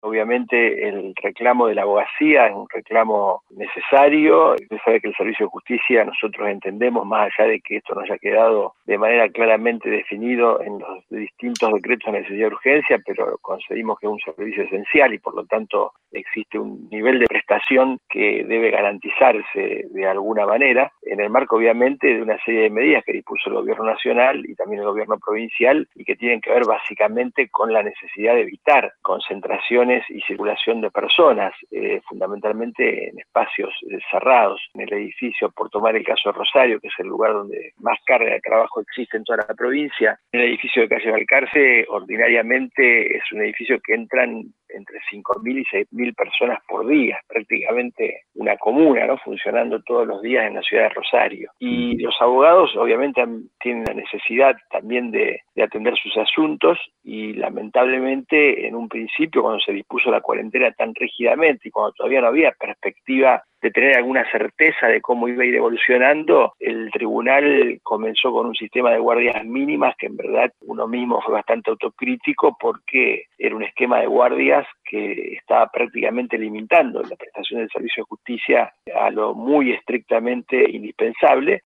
El ministro de la Corte Suprema de Santa Fe, Daniel Erbetta, dialogó con el programa Con Sentido Común, de CNN Radio Rosario, sobre la merma en la actividad del Poder Judicial durante la cuarentena, ante el pedido de los abogados que solicitaban mayor agilidad en los trámites.